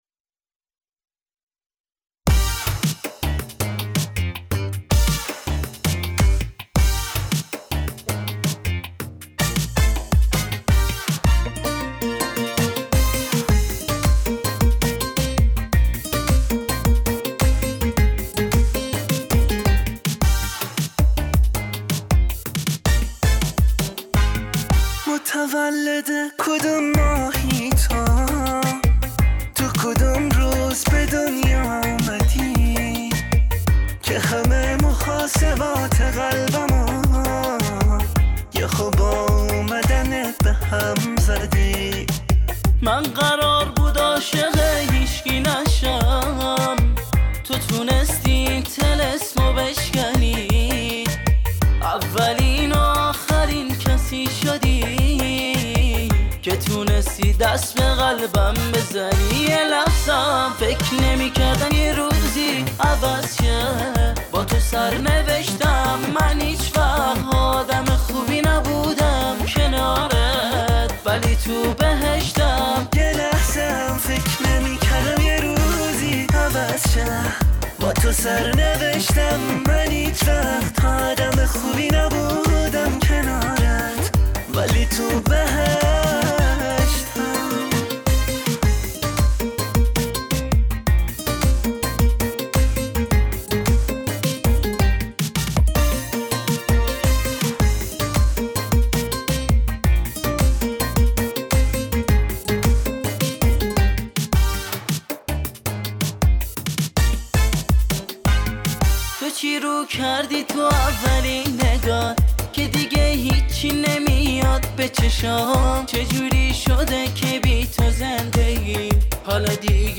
ریتم شاد